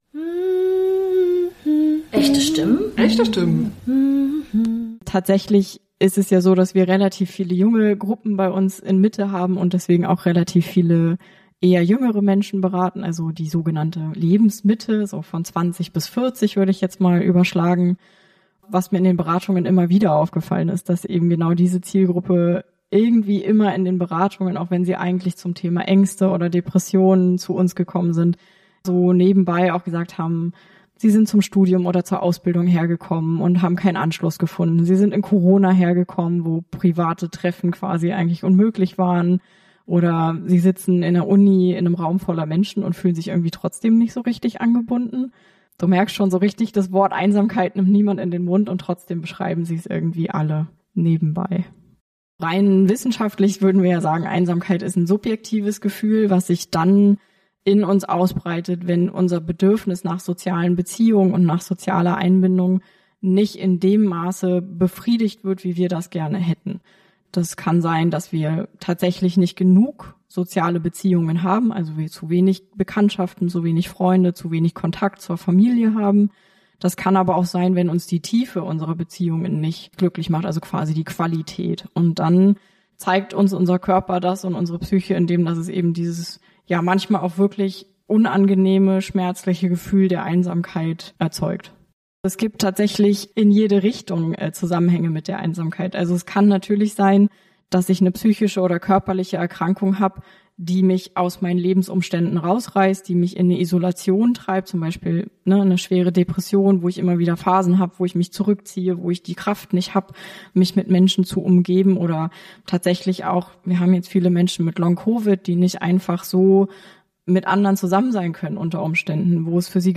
Wir haben in dieser Folge dazu Menschen aus der Jungen Selbsthilfe interviewt und dabei haben sie ein Projekt vorgestellt, was eine Möglichkeit für Junge Menschen aus der Selbsthilfe in Berlin sein kann, aus der Einsamkeit herauszukommen.